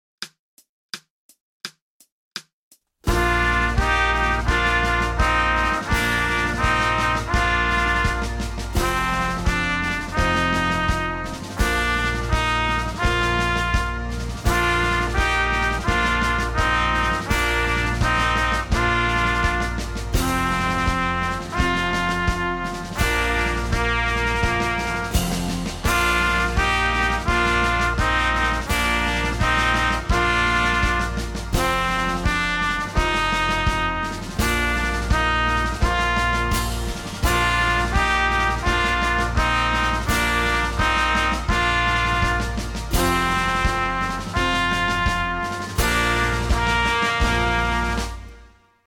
Trombone